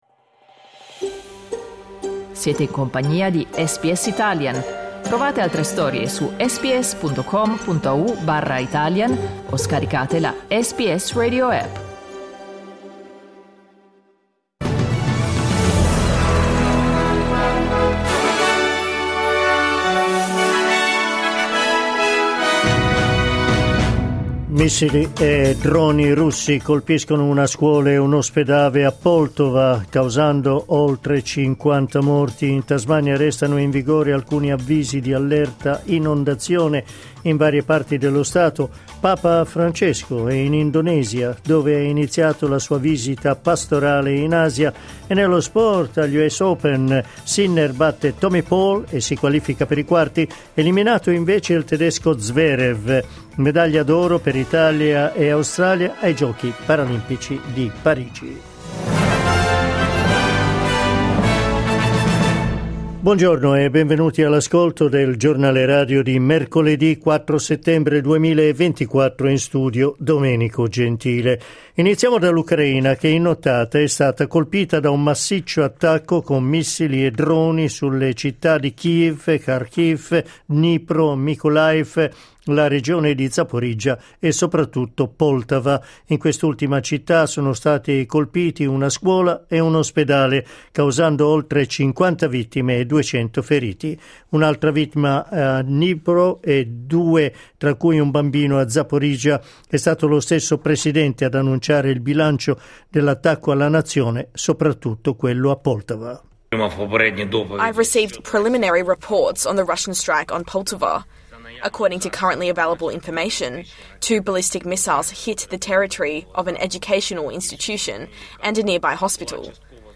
Giornale radio mercoledì 4 settembre 2024
Il notiziario di SBS in italiano.